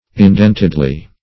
indentedly - definition of indentedly - synonyms, pronunciation, spelling from Free Dictionary Search Result for " indentedly" : The Collaborative International Dictionary of English v.0.48: Indentedly \In*dent"ed*ly\, adv.